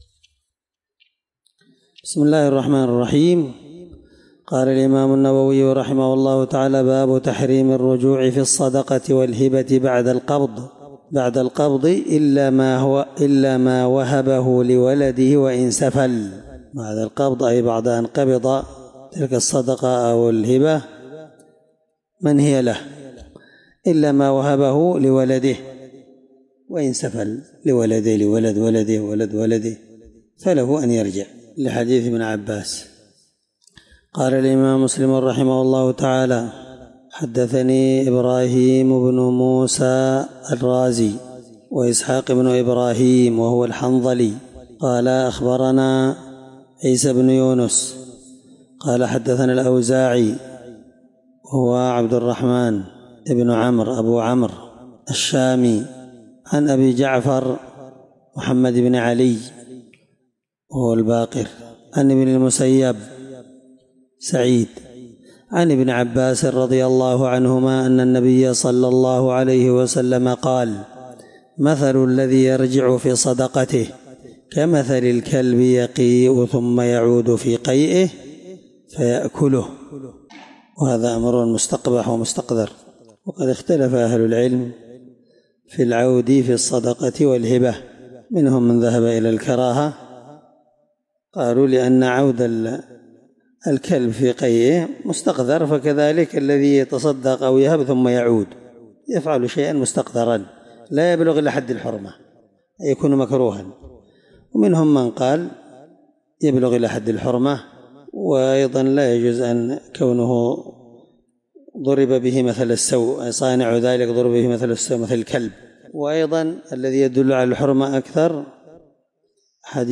الدرس2من شرح كتاب الهبات حديث رقم(1622) من صحيح مسلم